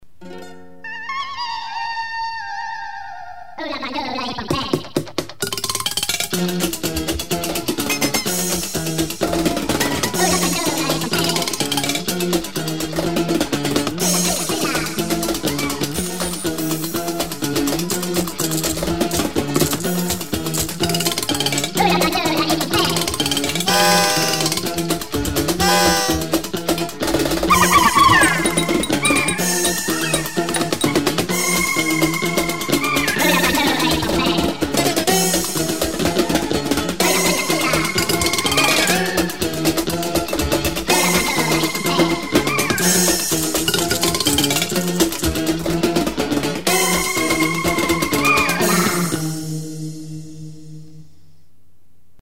Percussion instruments
Guitar, Voice, Various instruments